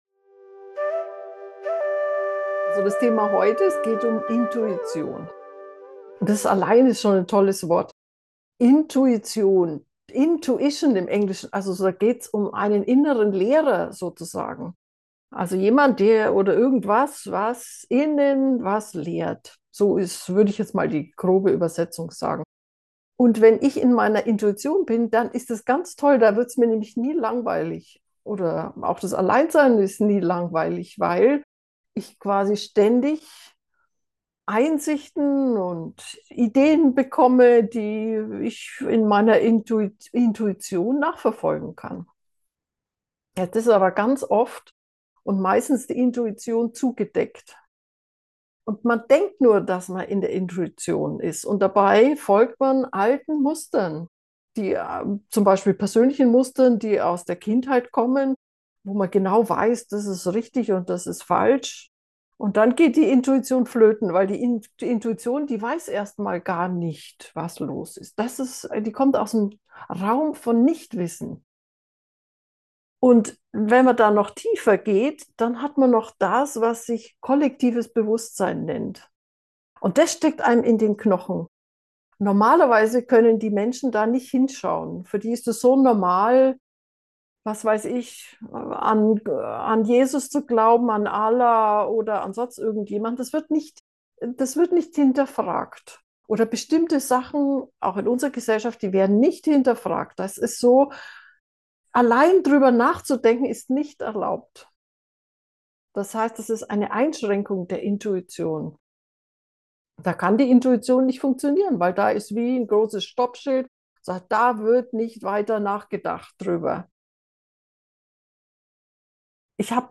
Das hilft dir, dich beim nächsten Mal klar für deine Intuition und deinen eigenen Weg zu entscheiden. Die Meditation wird im Sitzen durchgeführt und kann jederzeit gemacht werden.
Geführte Meditationen Folge 233: Deine Intuition oder eine innere Stimme der anderen?